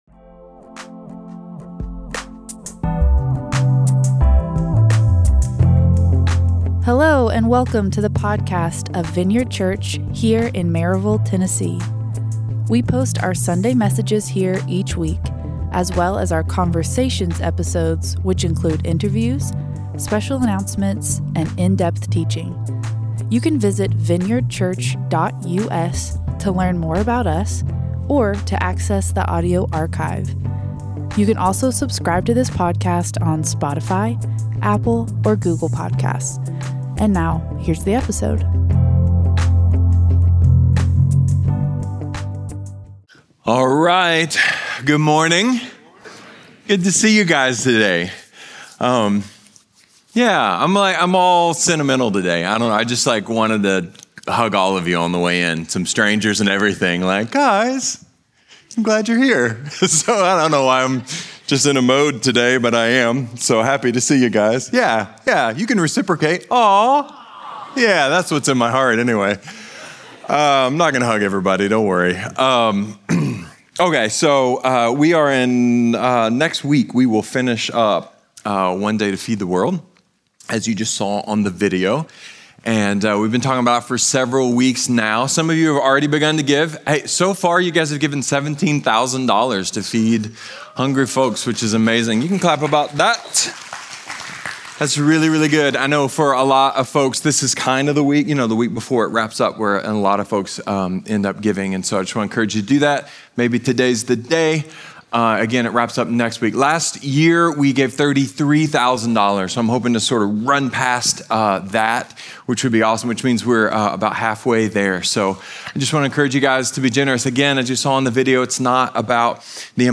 A sermon about freedom, interdependence, and a creepy box of mannequin parts.